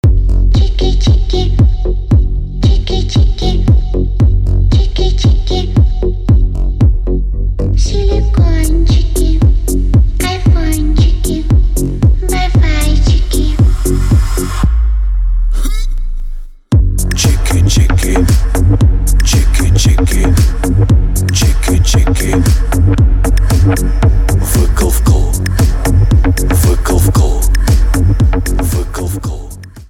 deep house
женский голос